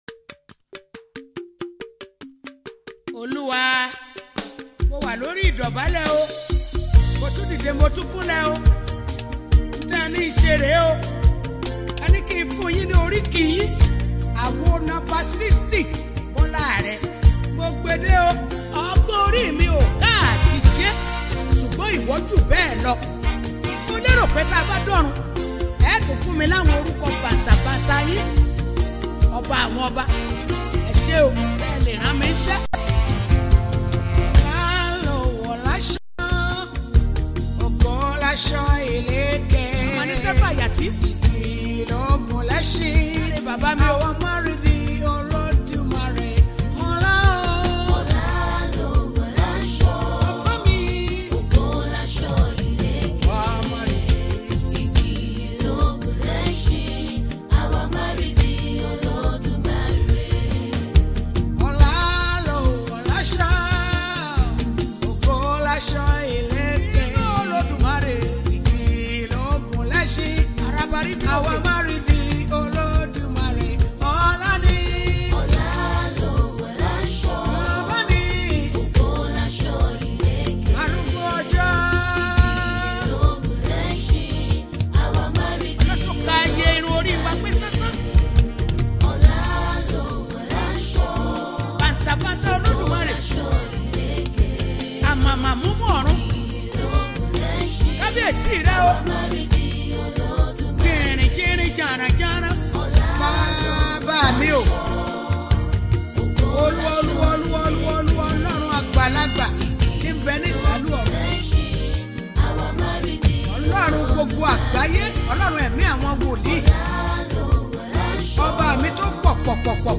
throwback song
inspirational gospel